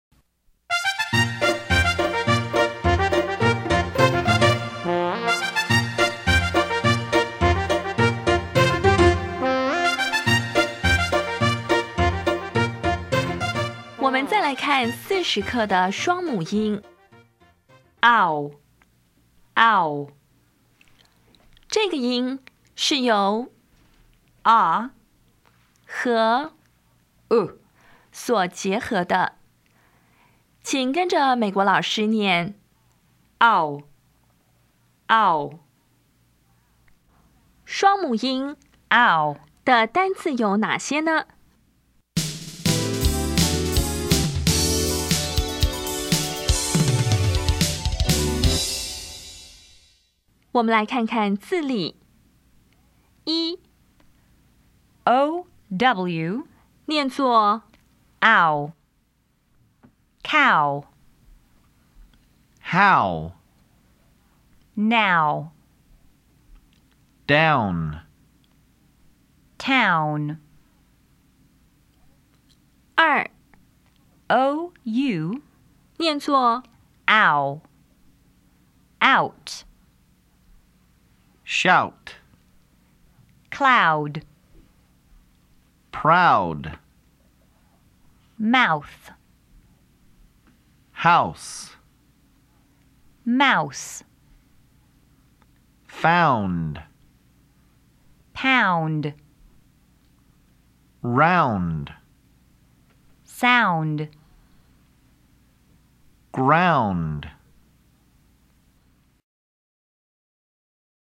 当前位置：Home 英语教材 KK 音标发音 母音部分-3: 双母音 [aʊ]
音标讲解第四十课
[kaʊ]
[naʊ]